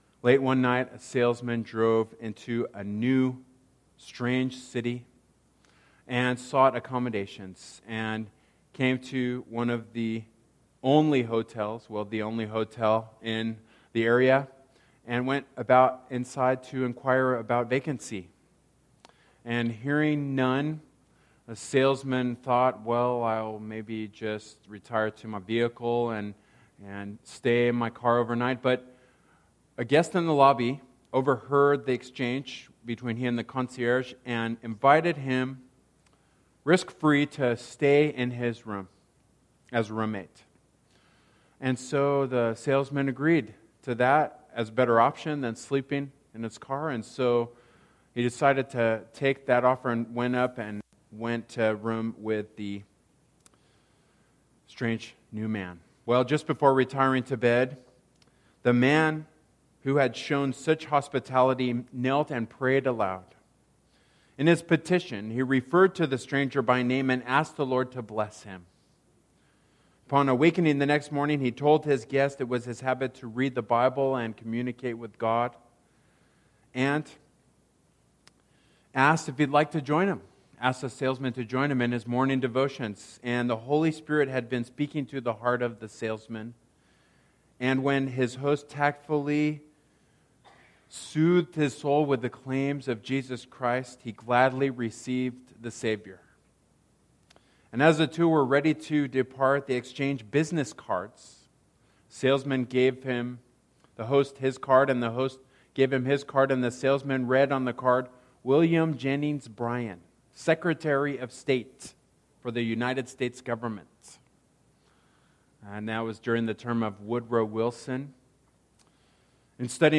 Service Type: Worship Service Topics: Salvation